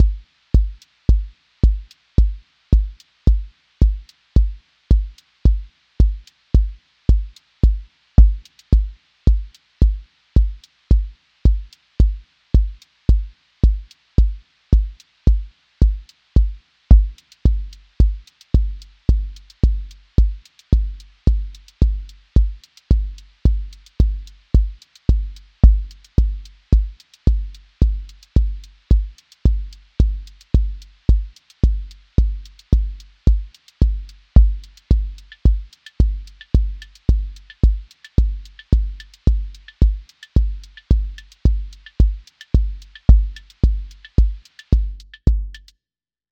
Build a lofi piece where three detuned sine clusters (3-5 partials each, slightly detuned) create beating frequencies that serve as the rhythmic foundation — no conventional percussion. The clusters shift slowly in detuning depth, causing beating rates to accelerate and decelerate, producing implied groove. A sub-bass drone from a single detuned sine pair anchors the low end. Vinyl crackle texture for lofi degradation.
• macro_house_four_on_floor
• voice_kick_808
• texture_vinyl_hiss